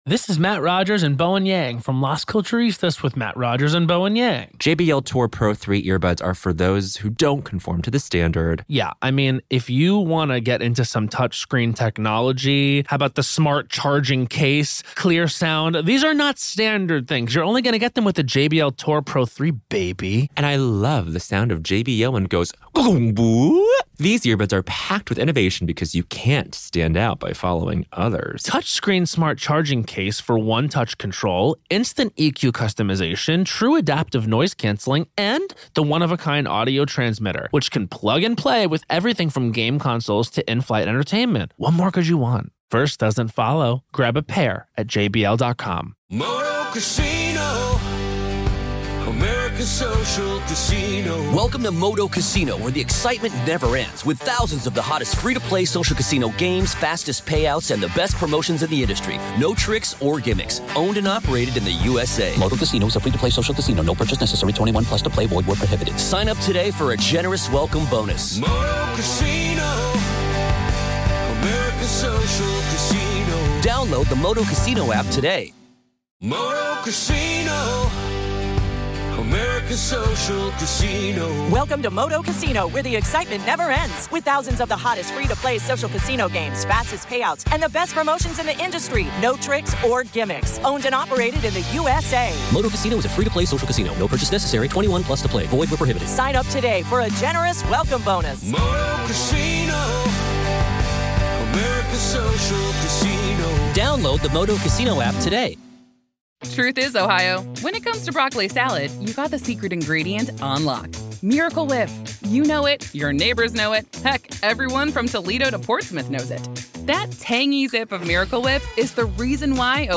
Each recording features the full day’s testimony, witness questioning, objections, rulings, and all live developments direct from the courtroom — presented exactly as they happened, without edits or commentary.